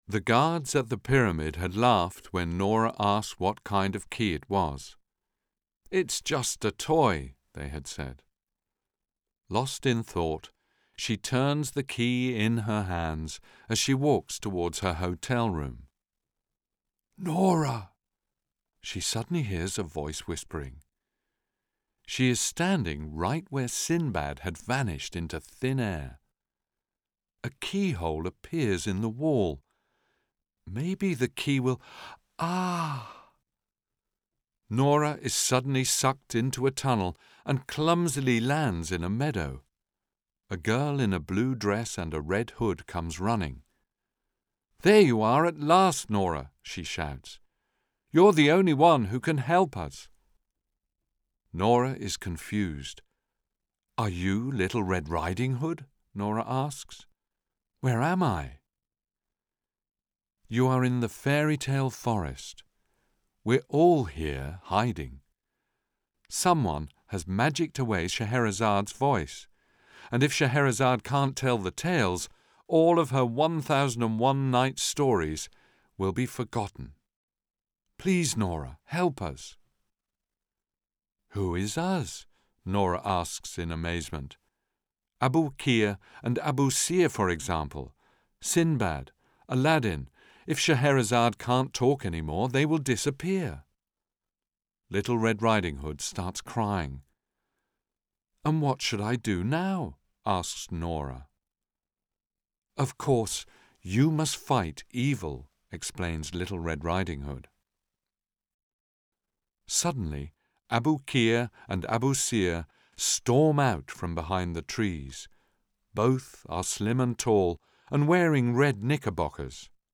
Geschichtenerzähler und Darsteller präsentieren zeitgenössische Geschichten und reflektieren, wie sich ihr Beruf verändert hat.
Storytellers and performers present contemporary tales and reflect on how their profession has changed.